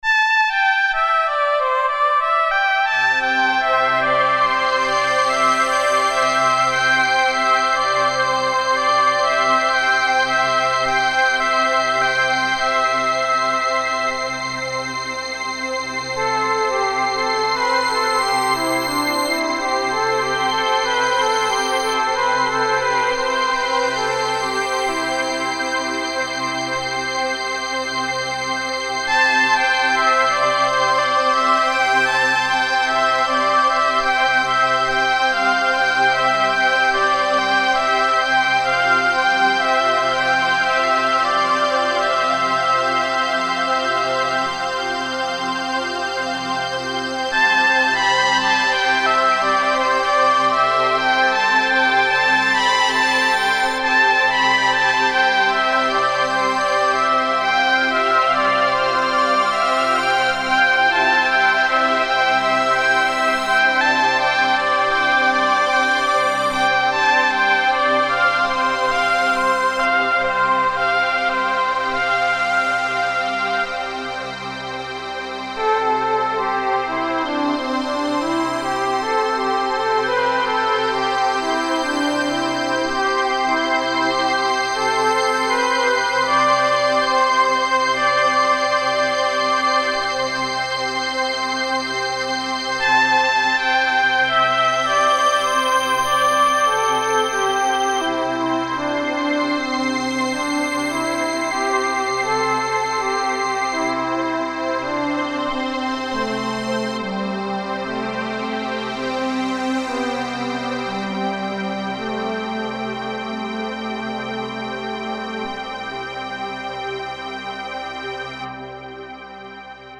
Yamaha WX 11
Hier ein erstes Soundbeispiel, bei dem ich mit dem WX-11 einen Synthie-Oboen-Lead-Sound spiele: Art_Jup8_wx11d